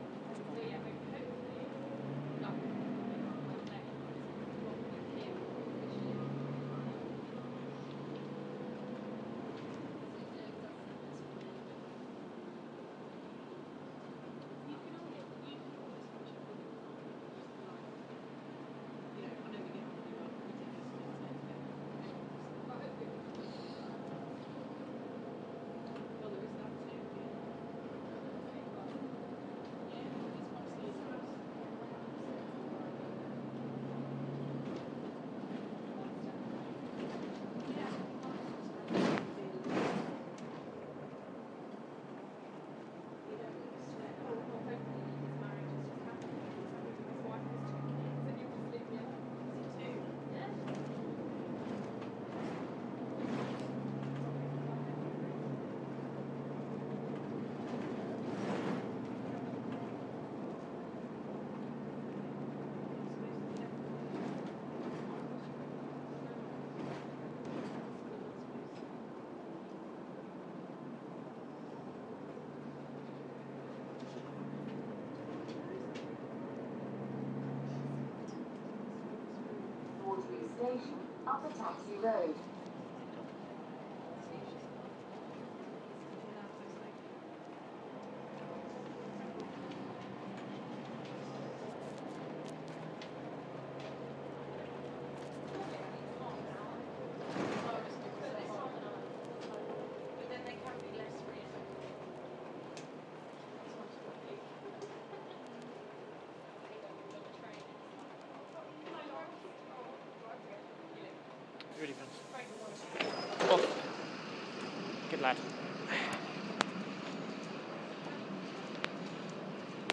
Catching the train at Waterloo, without commentary